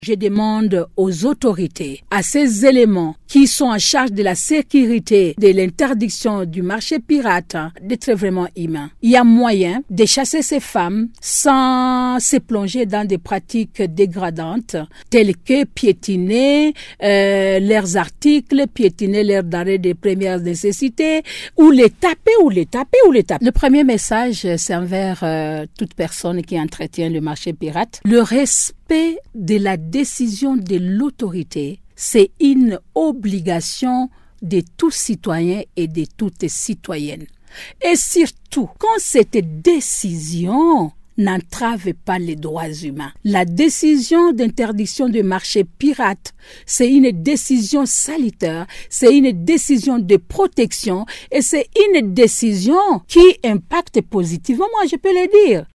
au cours d’un entretien accordé à RADIO MAENDELEO